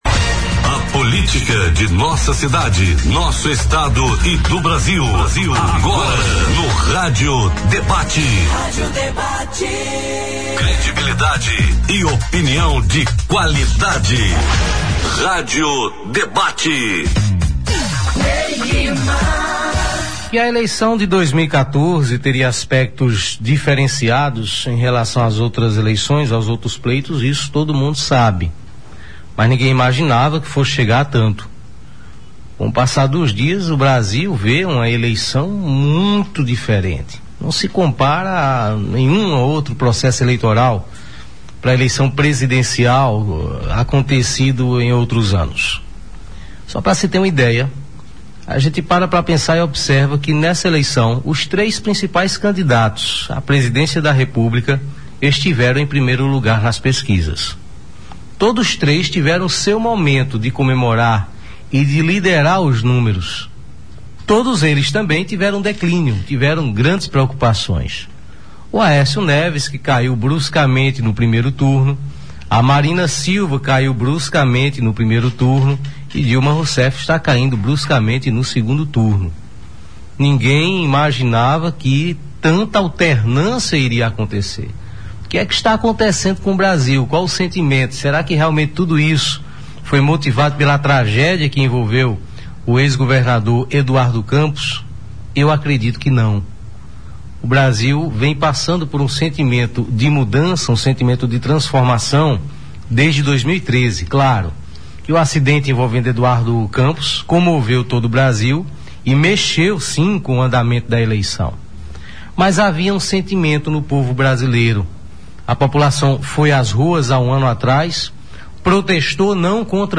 Os comentaristas citaram a decisão da candidata derrotada à presidência pelo PSB, Marina Silva, que anunciou seu apoio ao candidato tucano Aécio Neves no segundo turno.